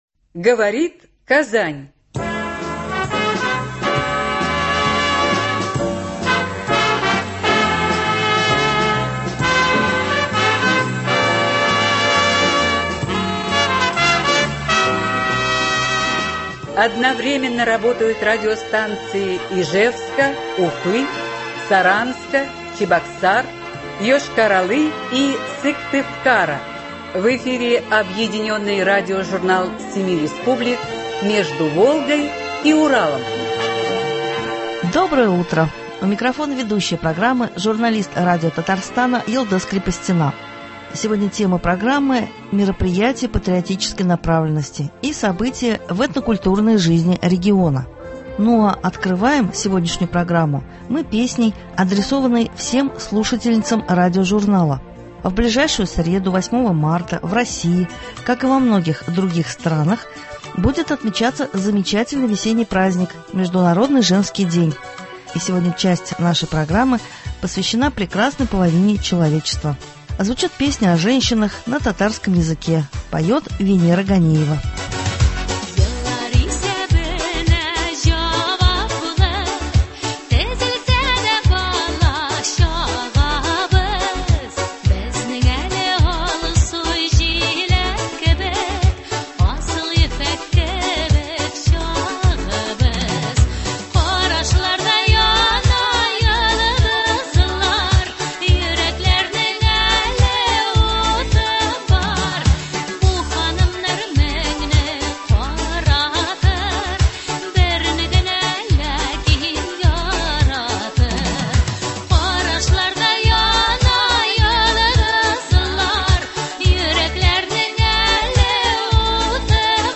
Объединенный радиожурнал семи республик.
Сегодняшнюю программу мы открываем песней, адресованной всем слушательницам радиожурнала.